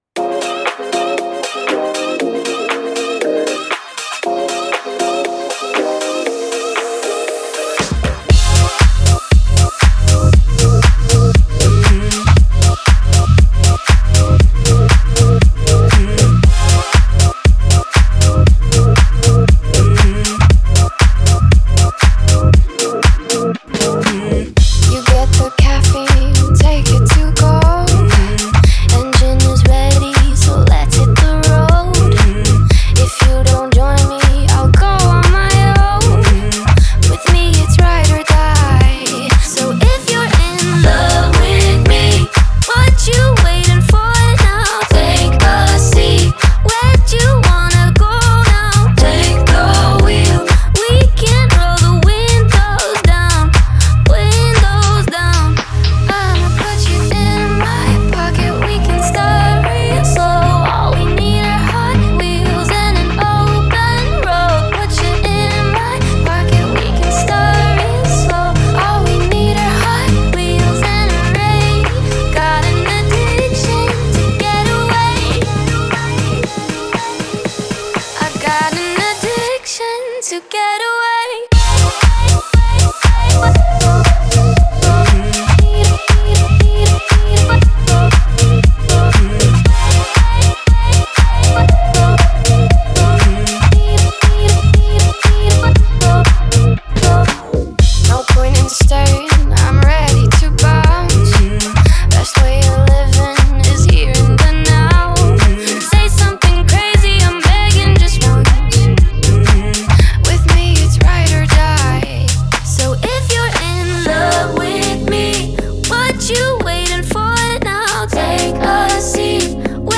захватывающая электронная танцевальная композиция